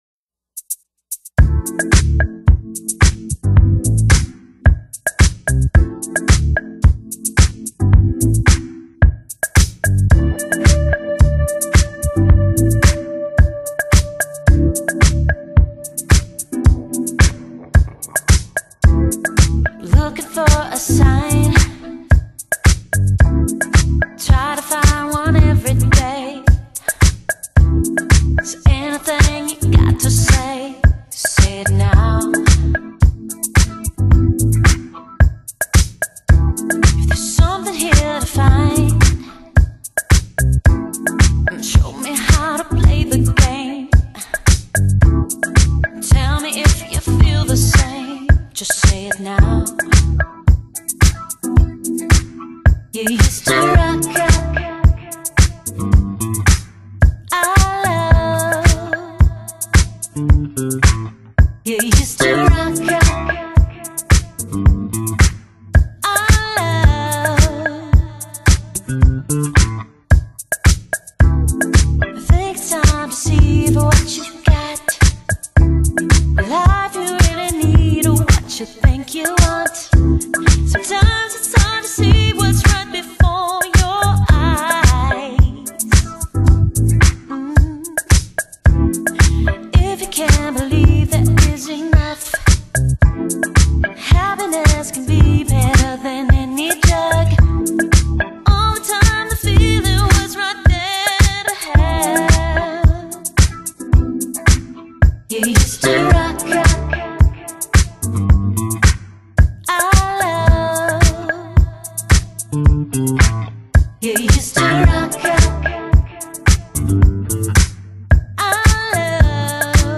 Genre: Chillout | House